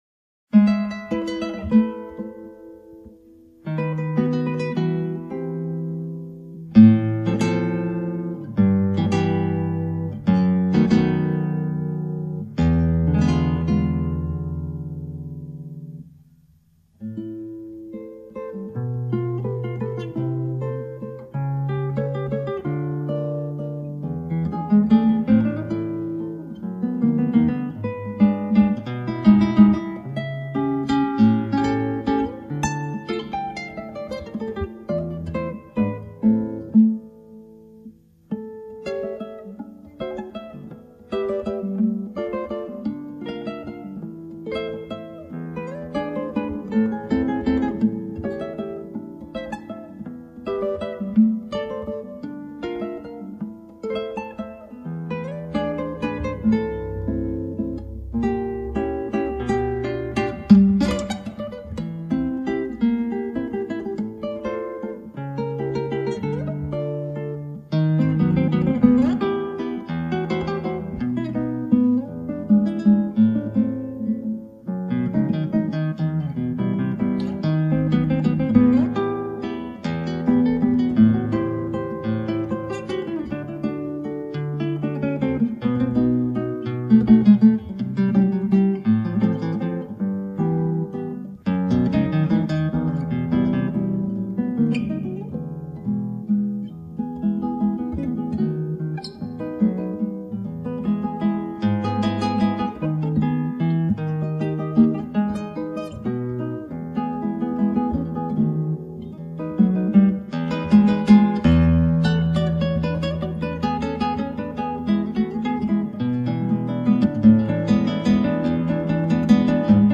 クラシックギター 情熱の空回り 編 - 「情熱のマズルカ」
ギターの自演をストリーミングで提供
情熱の空回り 編 「情熱のマズルカ」 04/05/02 「情熱のマズルカ」 情熱のマズルカ（Mazurka Apasionada）・・・すごい曲名だ このバリオスの曲、好きな曲なんだけどひどく難しいんだよな・・・。 本人は情熱をもって弾いてるんだけどどうも情熱が空回りしているようです。
リズムもマズルカじゃないし、表現も稚拙・・・ まあ、激闘らしくていいか、こんなもんで。